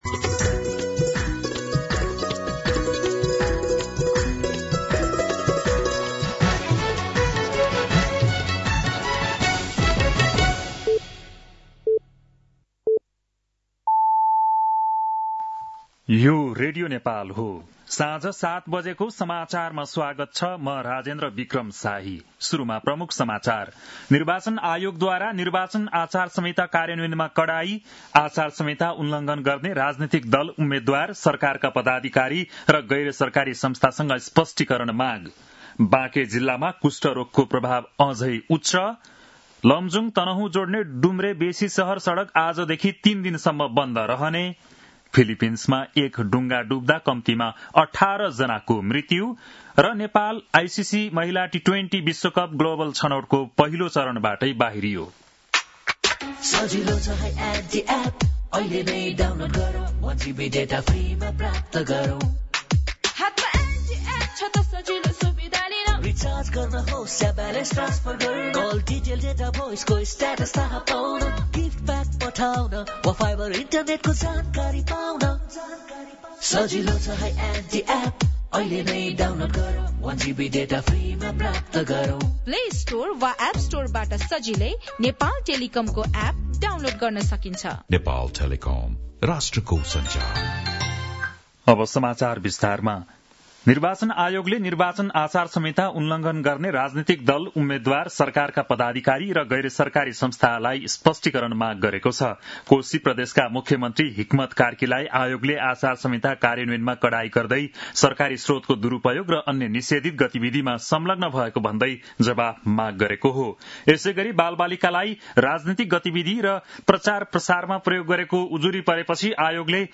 बेलुकी ७ बजेको नेपाली समाचार : १२ माघ , २०८२